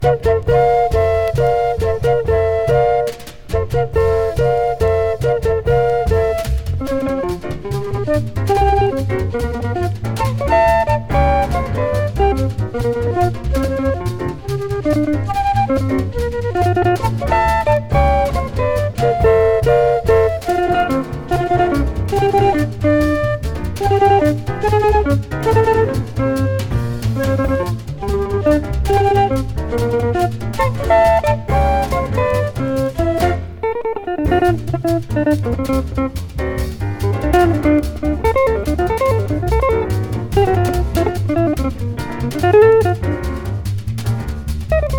Jazz, Cool Jazz　Japan　12inchレコード　33rpm　Mono